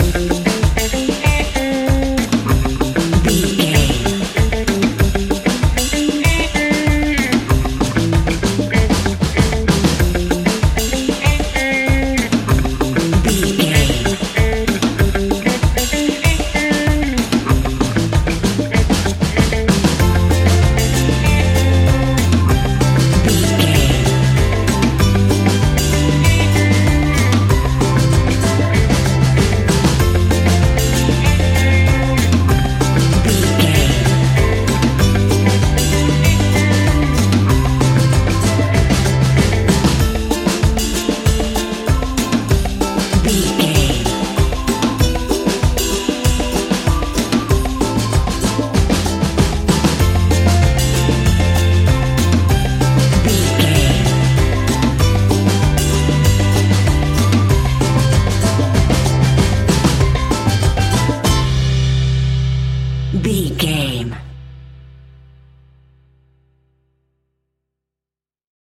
Uplifting
Ionian/Major
steelpan
drums
percussion
bass
brass
guitar